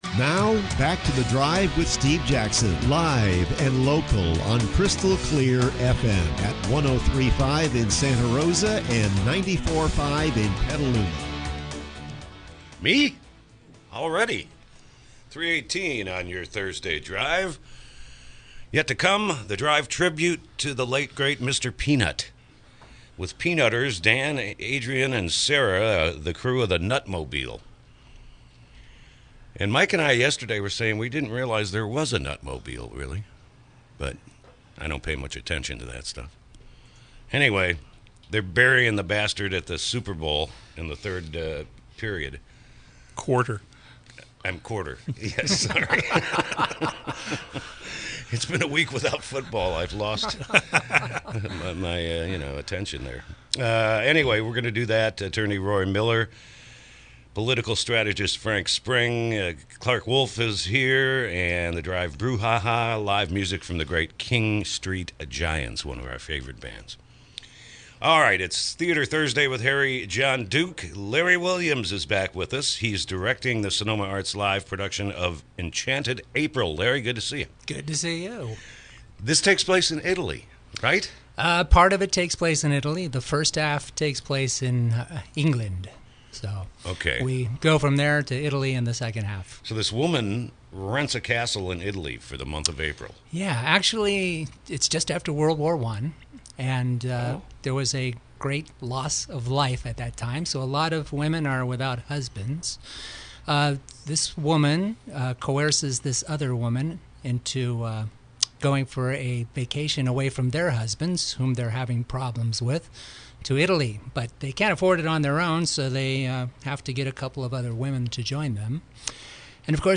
KSRO Interview – “Enchanted April”